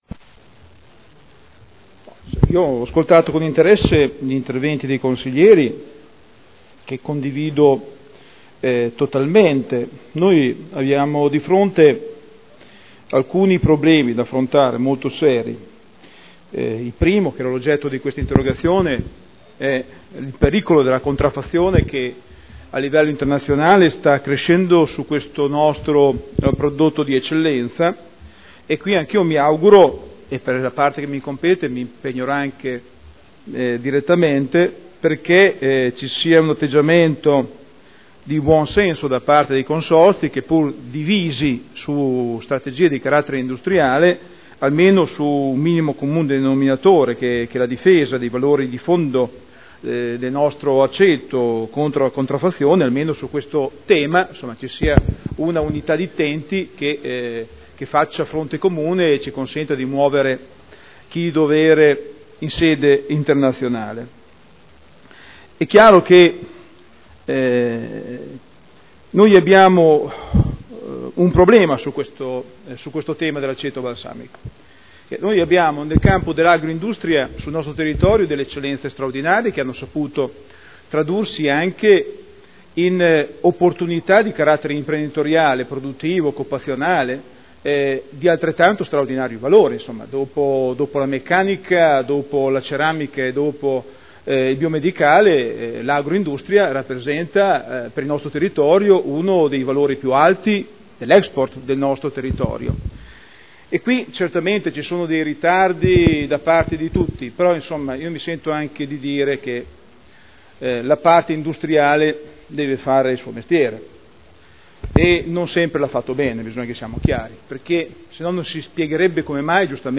Daniele Sitta — Sito Audio Consiglio Comunale